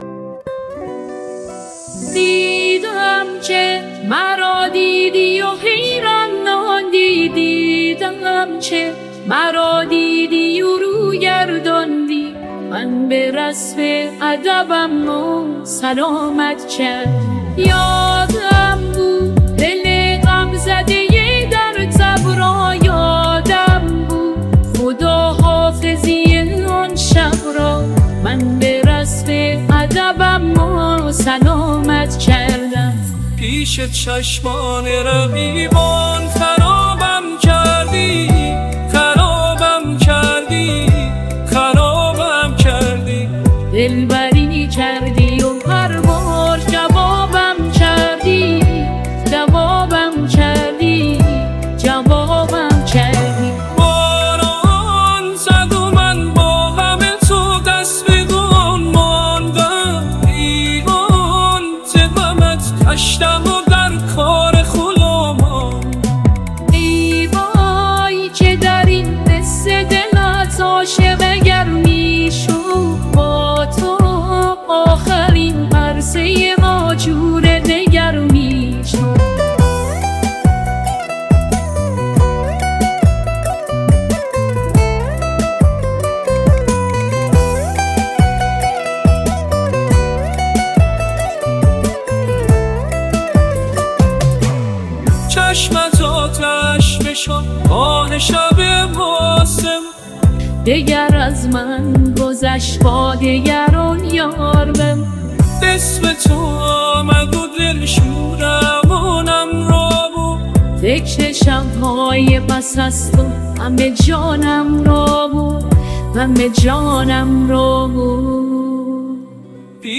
دانلود آهنگ پاپ ایرانی